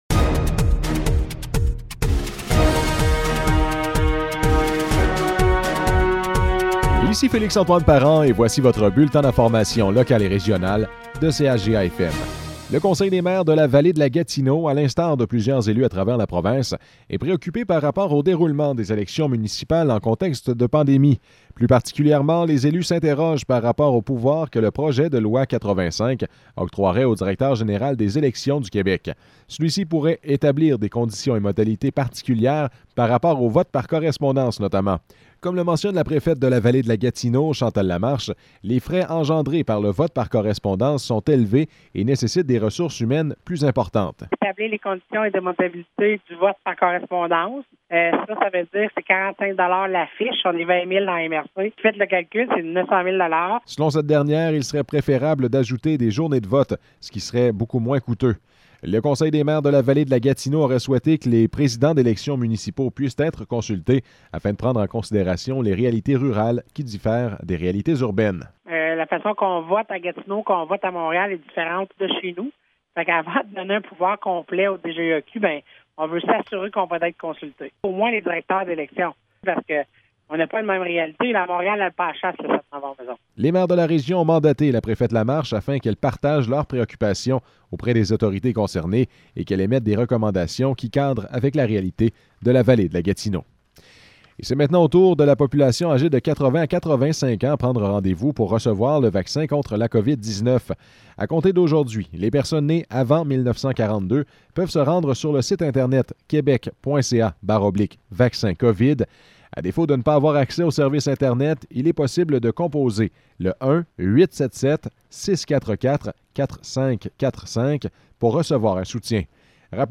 Nouvelles locales - 2 mars 2021 - 12 h